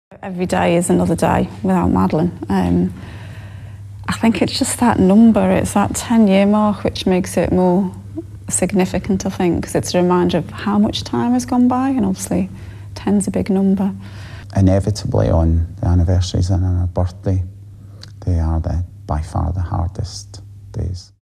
Rocznice są najtrudniejsze mówią w wywiadzie dla BBC rodzice Maddie.